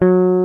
FLYING V 4.wav